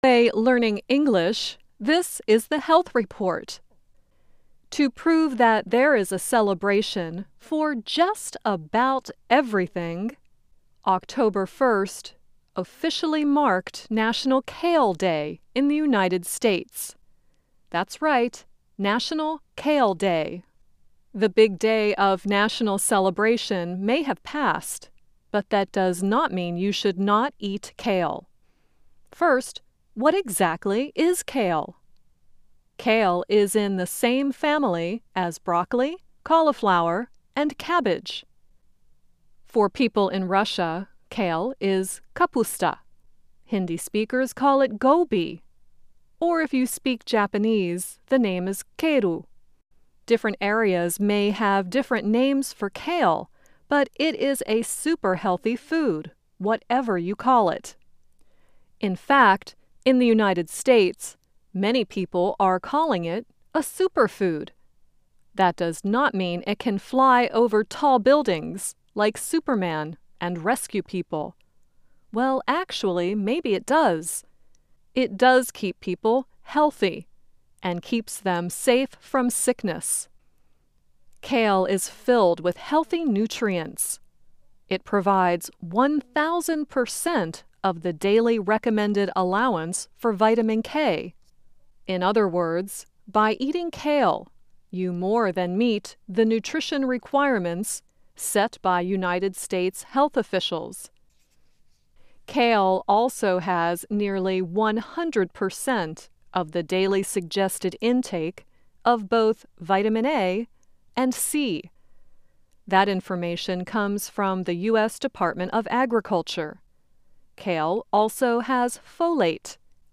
Learn English as you read and listen to a weekly show about developments in science, technology and medicine. Our stories are written at the intermediate and upper-beginner level and are read one-third slower than regular VOA English.